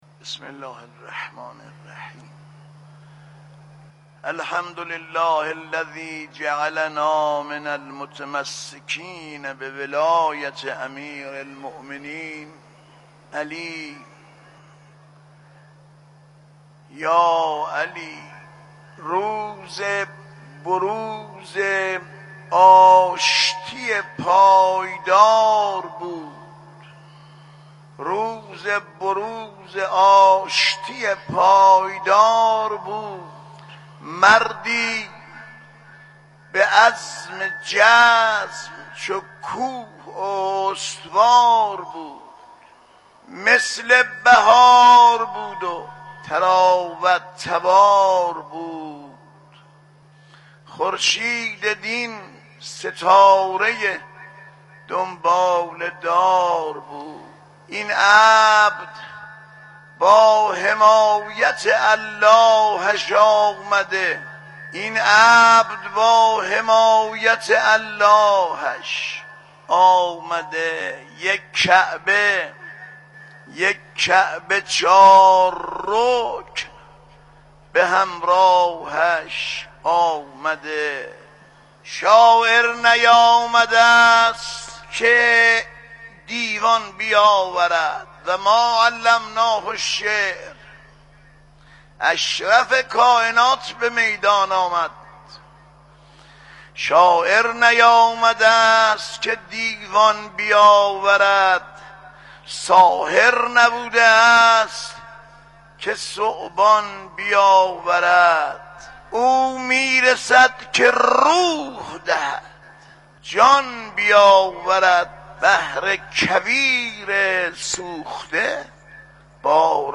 علی انسانی مداح اهل بیت (ع) و شاعر آئینی دیروز جمعه ۲۳ تیرماه پیش از خطبه‌های نماز جمعه تهران به شعرخوانی و روضه‌خوانی پرداخت.